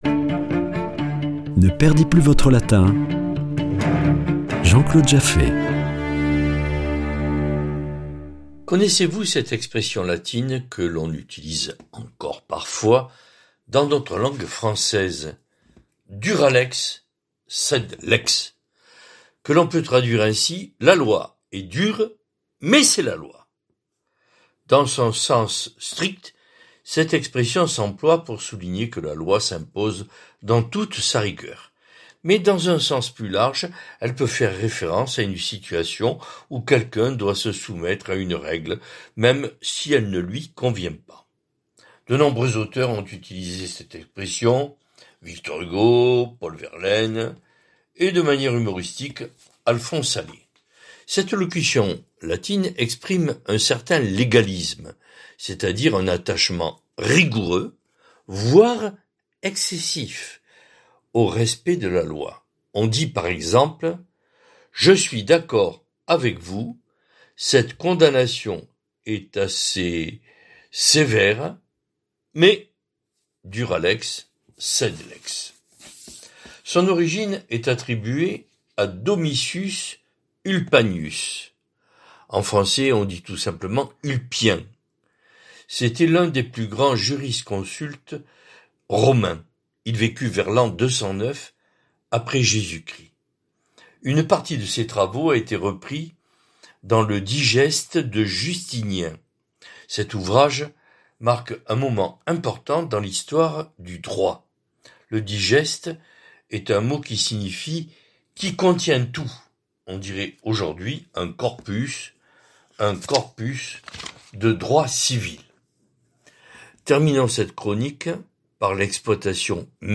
Chronique Latin